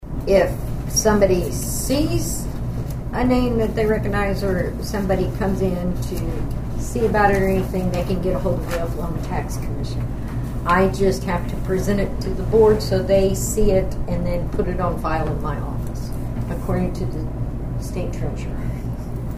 The Nowata County Commissioners met for a regularly scheduled meeting on Monday morning at the Nowata County Annex.
County Clerk Kay Spurgeon explained what the report is.